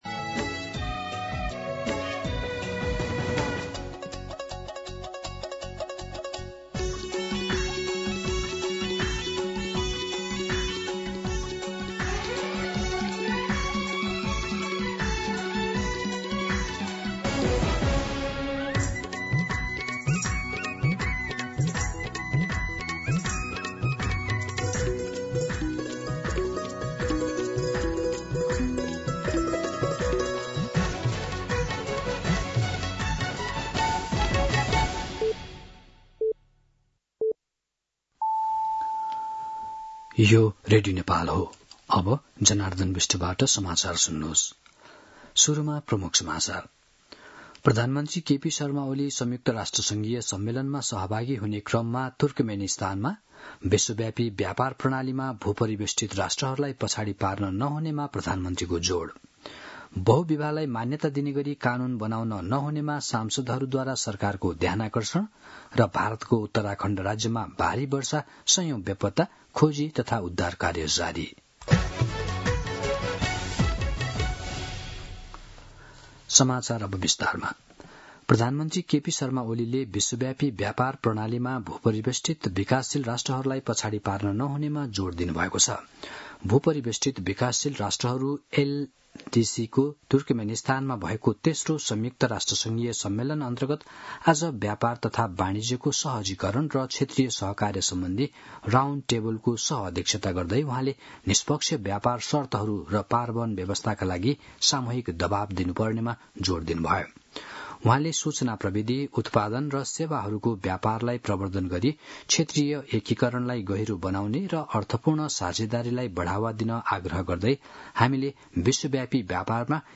दिउँसो ३ बजेको नेपाली समाचार : २१ साउन , २०८२